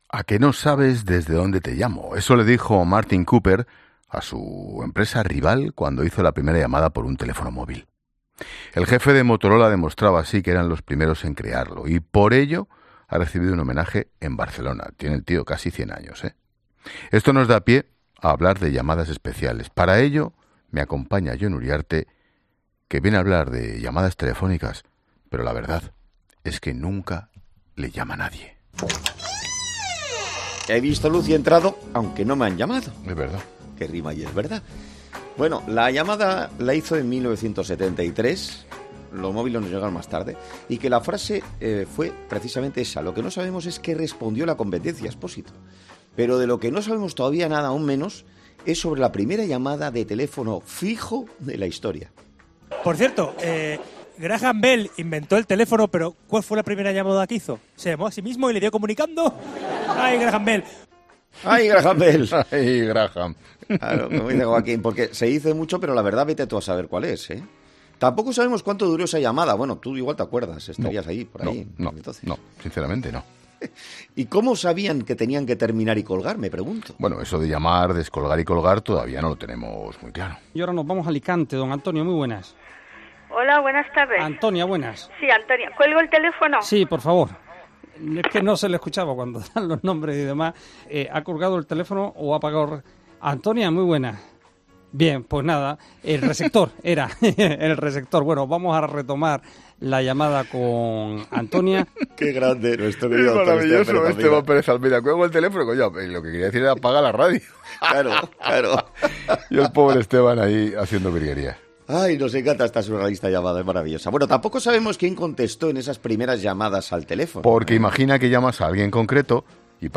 Expósito se parte de risa al escuchar una accidentada entrevista de 1986 en COPE a un ministro: "¡Qué bueno!"